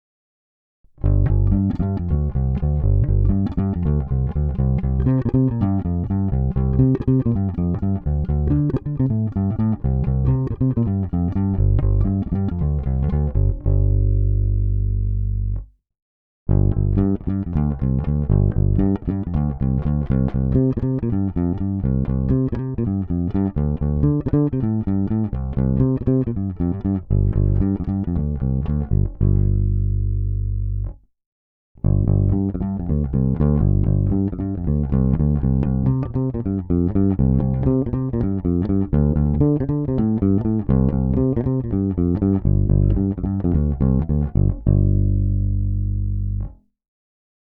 Snímač je kousavější, agresívnější, a to i přes použité hlazené struny Thomastik-Infeld Jazz Flat Wound JF344 (recenze), jinak klasický precižnovský charakter se nezapře. S nimi jsem provedl nahrávku rovnou do zvukovky.